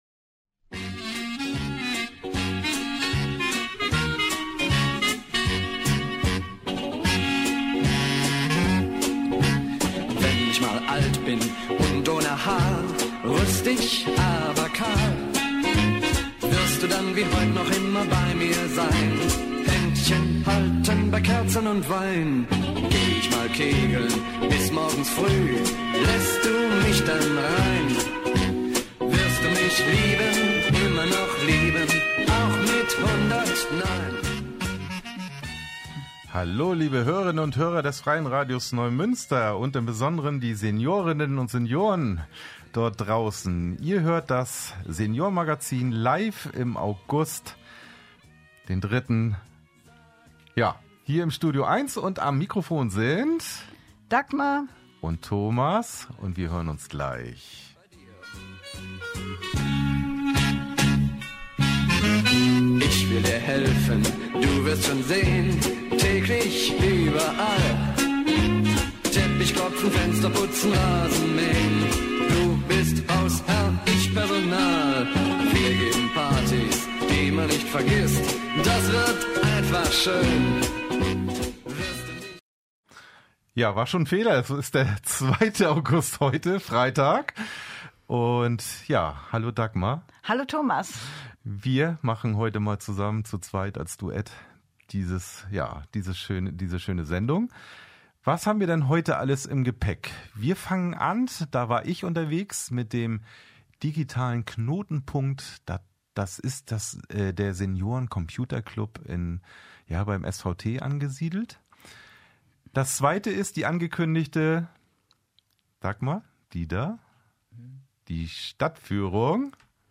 Einen Radiobeitrag �ber den Besuch findet man hier